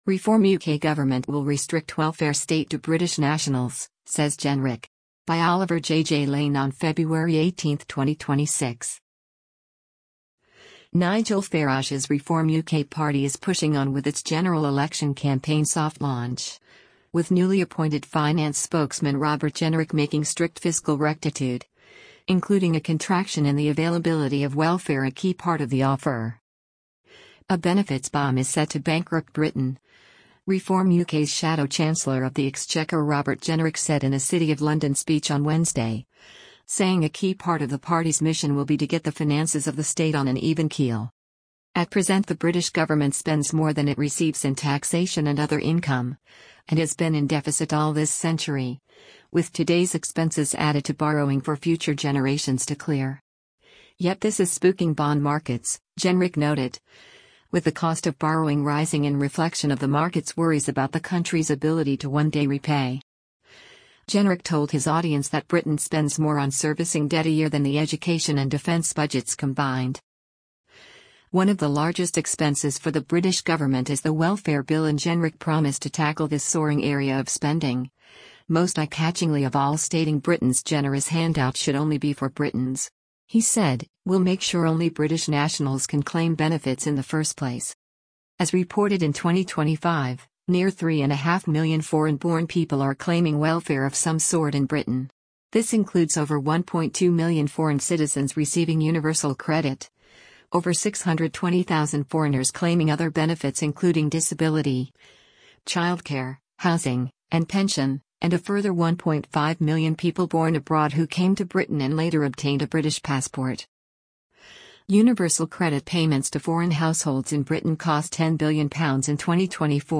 A “benefits bomb” is “set to bankrupt Britain”, Reform UK’s “shadow chancellor of the exchequer” Robert Jenrick said in a City of London speech on Wednesday, saying a key part of the party’s mission will be to get the finances of the state on an even keel.